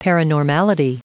Prononciation du mot paranormality en anglais (fichier audio)
Prononciation du mot : paranormality